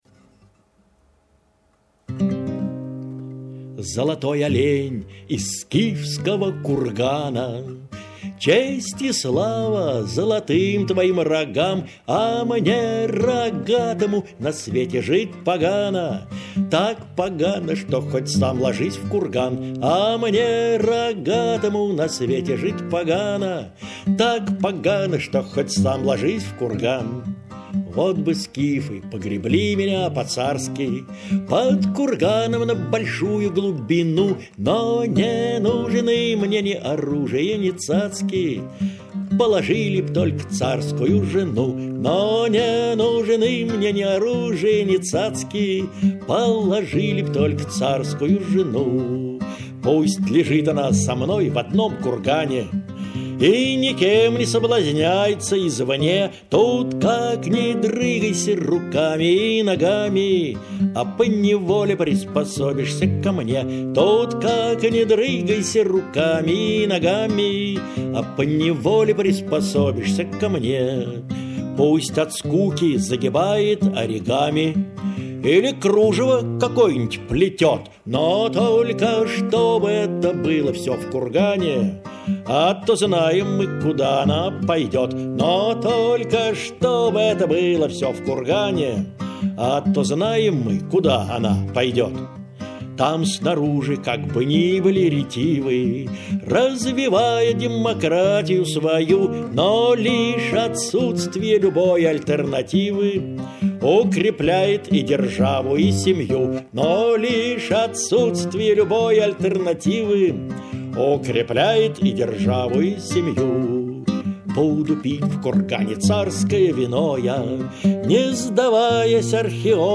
17 января 2015 г. состоялся 72-й вечер "Споём вместе!".